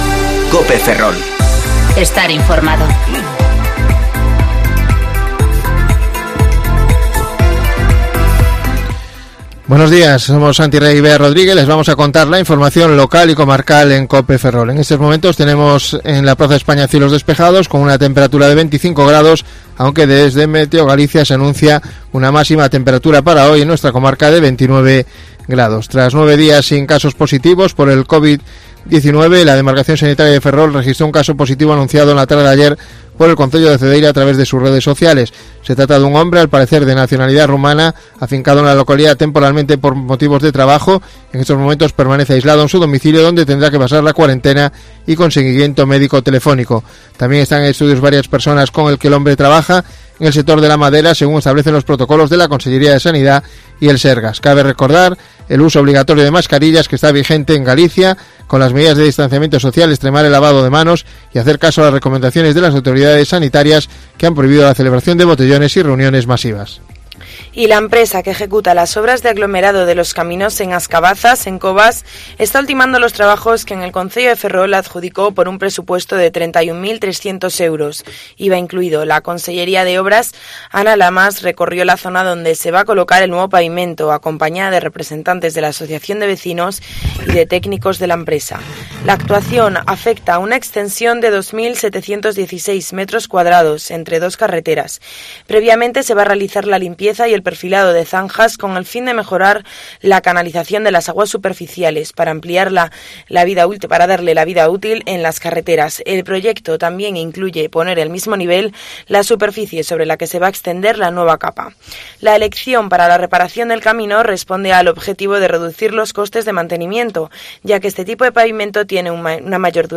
AUDIO: Informativo Cope Ferrol 29/07/2020 (De 14:20 a 14:30 horas)